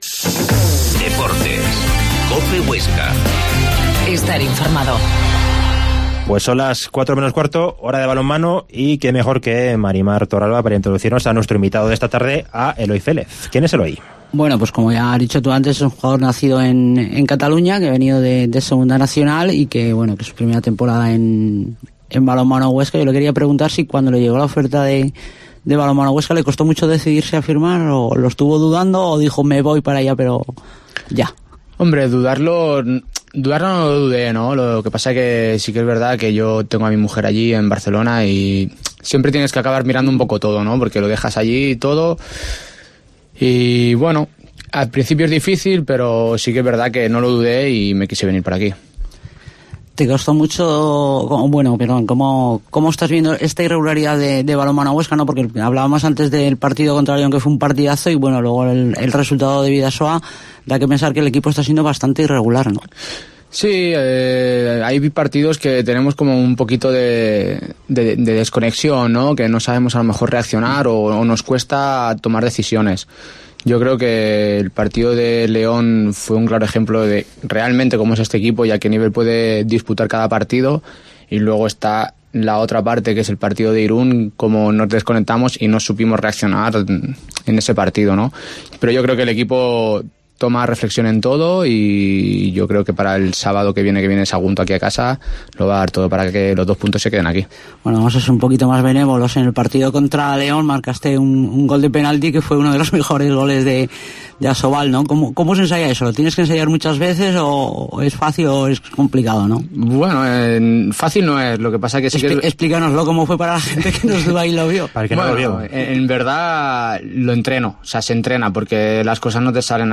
Entrevistamos al central del Bada Huesca en nuestros estudios para tratar la actualidad del club oscense que este fin de semana perdió dolorosamente...